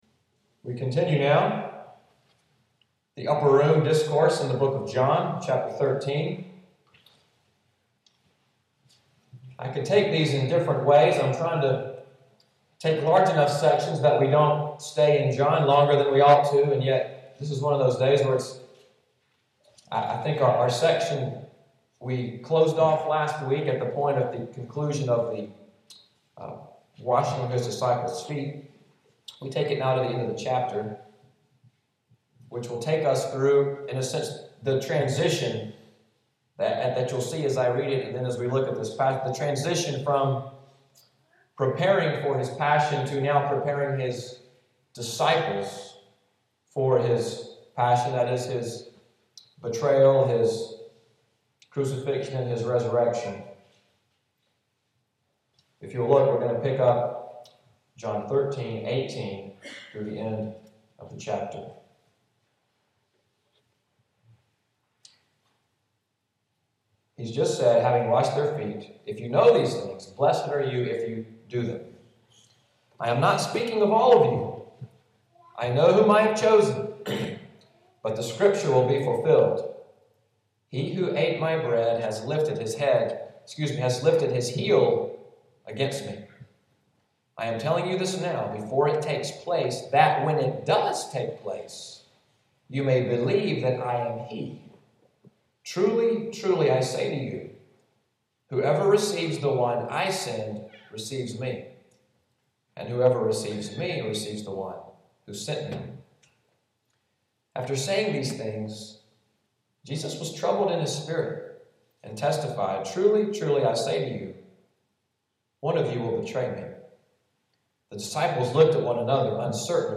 Audio of Sunday’s sermon, “As I Have Loved You,” November 17, 2013.